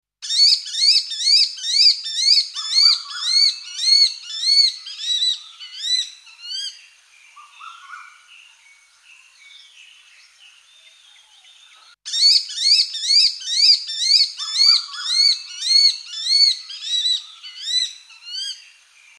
Shikra – the call uttered when pair copulating
9Shikra_copulation-call.mp3